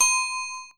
dingSFX1.aiff